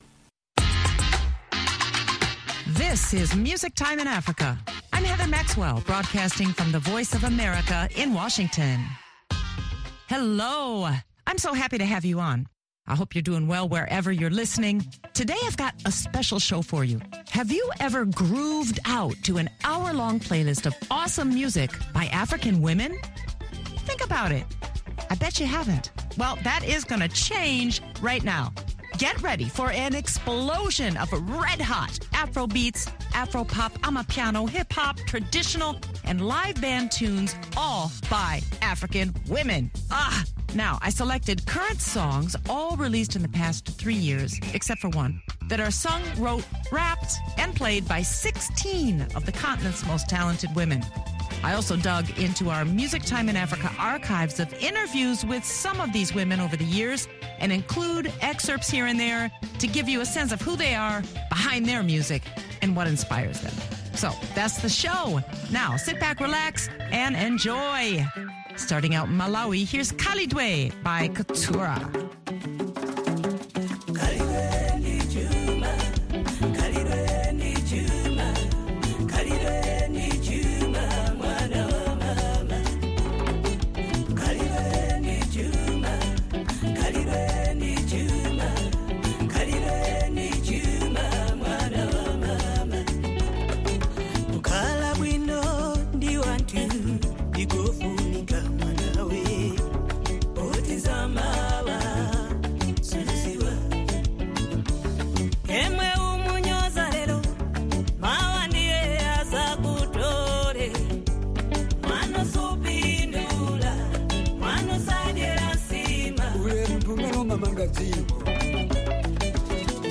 She also plays several interviews with some of Africa’s brightest female stars from the Music Time in Africa archives dating back to 2012 such as Oumou Sangare (Mali), Nomcebo Zikode (South Africa) and Sona Jobarteh (Gambia).